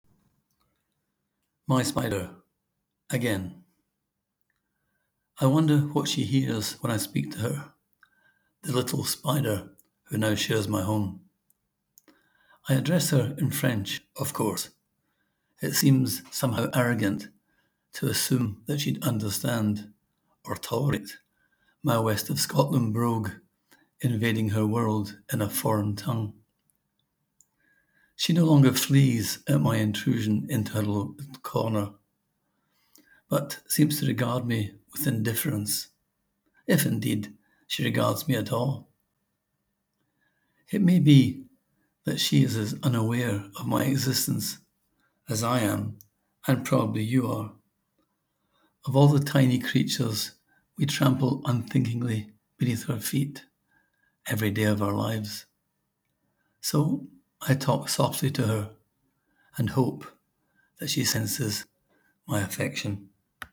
Click here to hear the poet read his words: